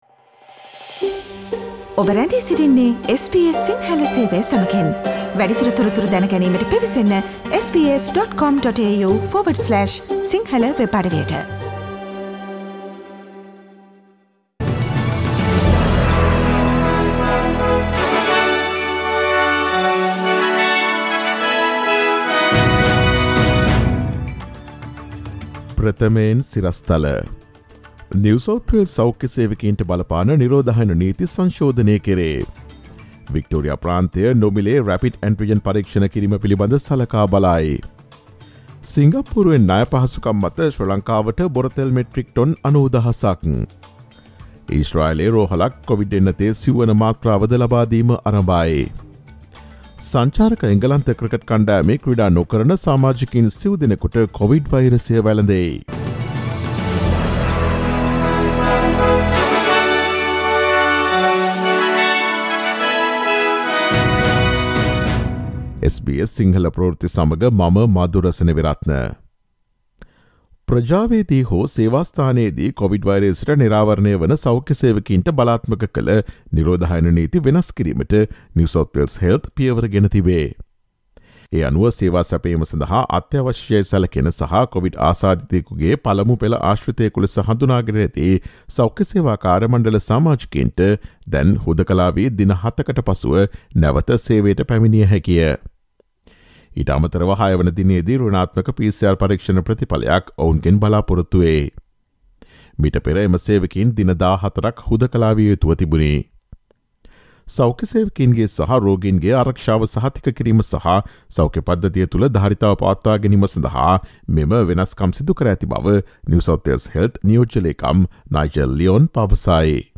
Australian News in Sinhala on 28 Dec: NSW Health changes its isolation rules for healthcare workers Source: AAP